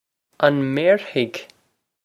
On mare-hig?
This is an approximate phonetic pronunciation of the phrase.